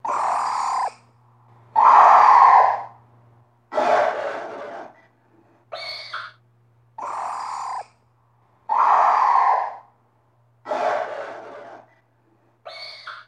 Inhuman Voice Effects